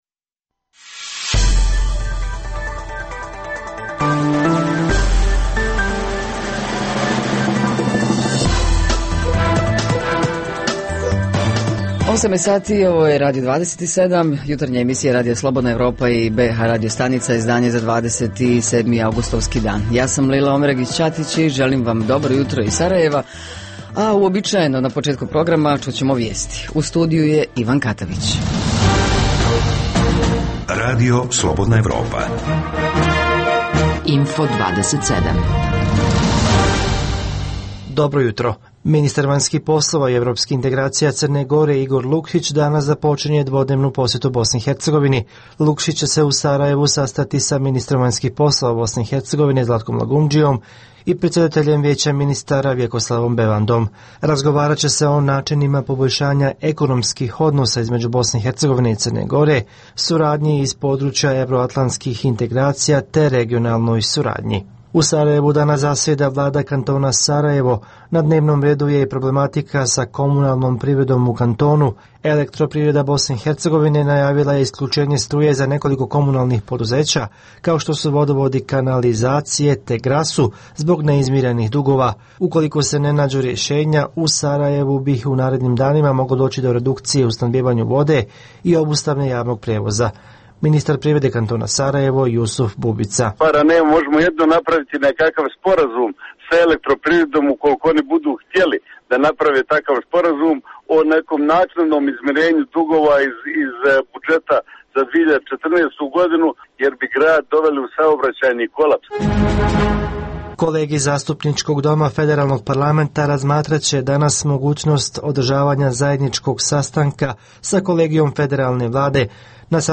U rubrici Info plus novosti iz Bijeljine i Tuzle saznajemo iz razgovora s našim dopisnicima iz ovih gradova. Iz programa RSE priča o bh parlamentarcima koji ne prestaju da govore o svojoj ugroženosti, čime skreću pažnju sa svog nerada.